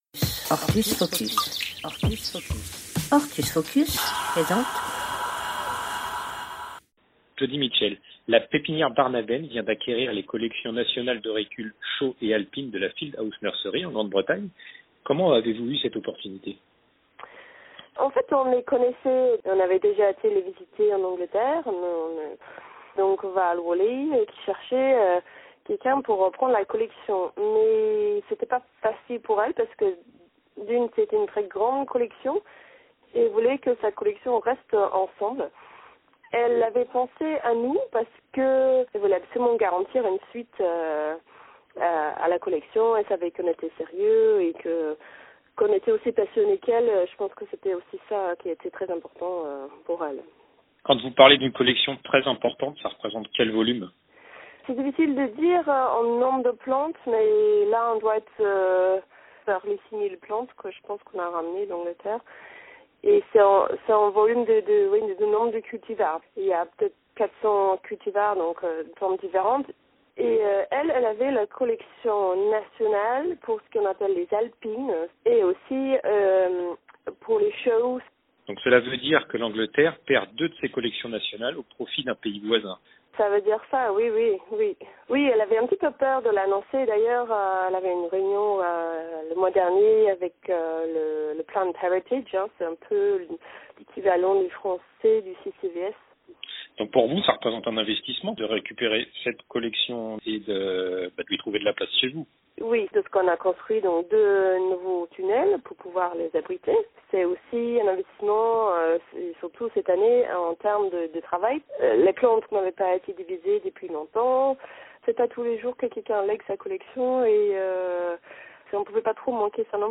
Mon entretien avec